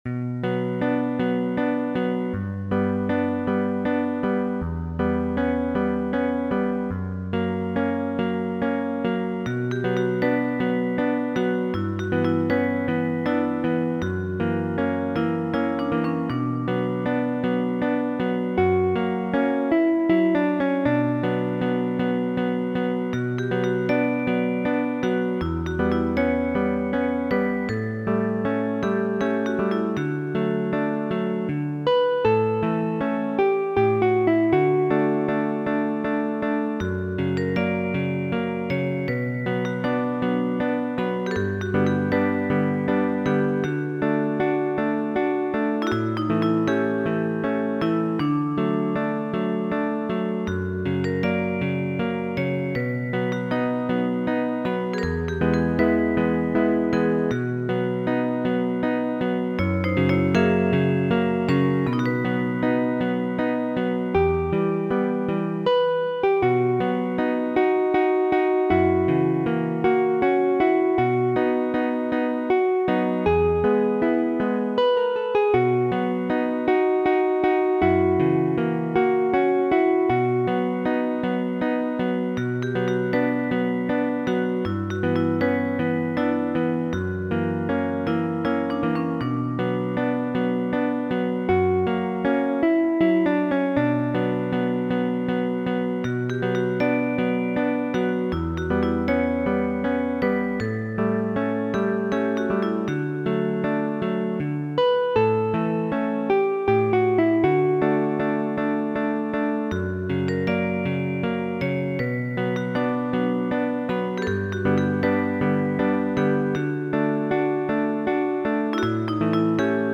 Serenata, de Franz Schubert, laŭ versio de Federiio Noad enkomputiligita de mi mem.